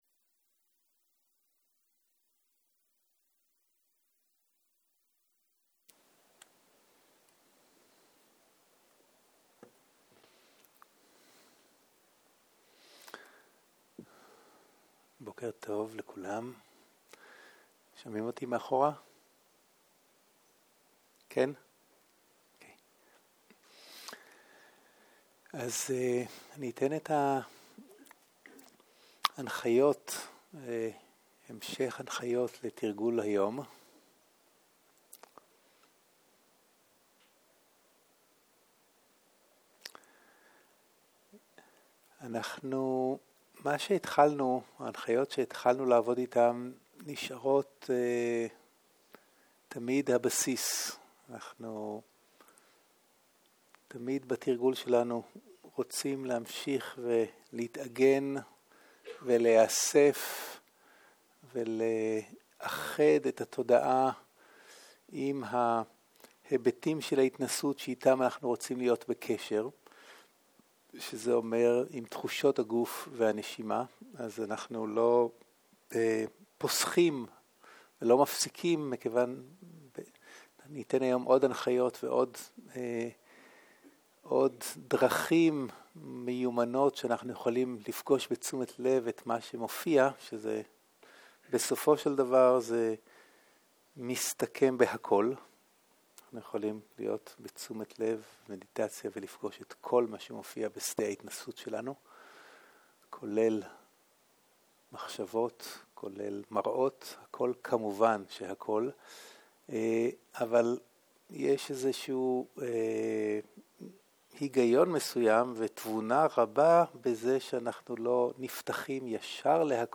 בוקר - מדיטציה מונחית - הנחיות להליכה ושאלות
סוג ההקלטה: מדיטציה מונחית